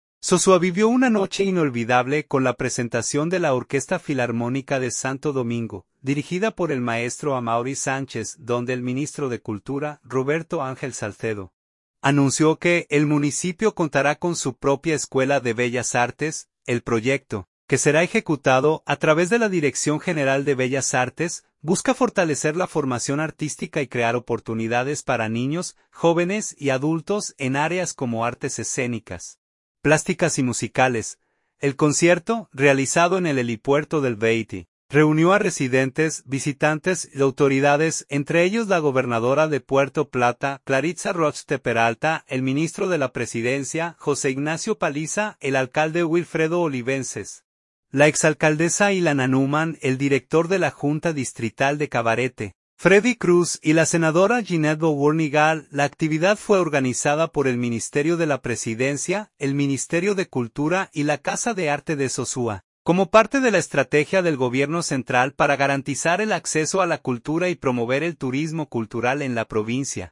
El concierto, realizado en el helipuerto del Batey, reunió a residentes, visitantes y autoridades, entre ellos la gobernadora de Puerto Plata, Claritza Rochtte Peralta, el ministro de la Presidencia, José Ignacio Paliza, el alcalde Wilfredo Olivences, la exalcaldesa Ilana Neumann, el director de la Junta Distrital de Cabarete, Freddy Cruz, y la senadora Ginette Bournigal.